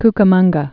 (kkə-mŭnggə)